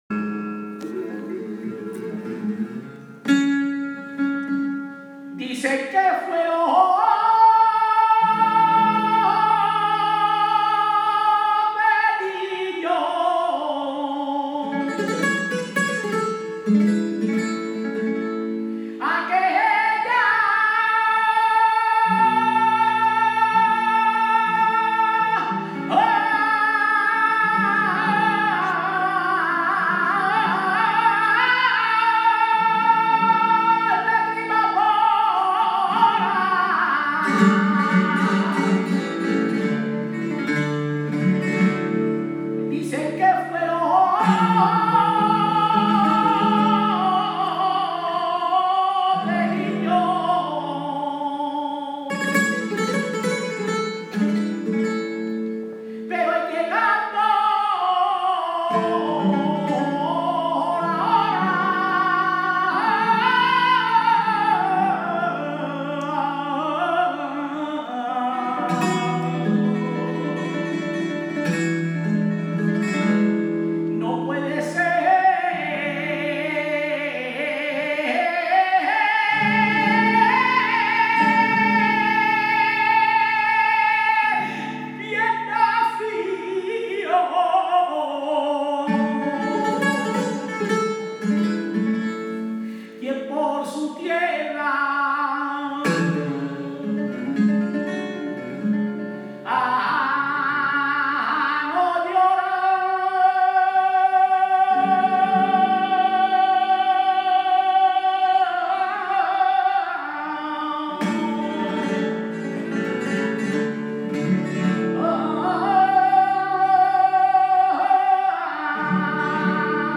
Una noche de duende y embrujo en un Centro Federico García Lorca abarrotado de público en general y muchas personas ciegas procedentes de las las ocho provincias andaluzas sin excepción. Olés y aplausos para el cante desgarrador de
y ahora lo recibió como cantaor.